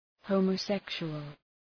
Προφορά
{,həʋmə’sekʃʋəl}